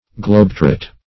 Search Result for " globetrot" : The Collaborative International Dictionary of English v.0.48: globe-trot \globe"-trot`\, globetrot \globe"trot`\v. i. to travel all over the world for pleasure and sightseeing.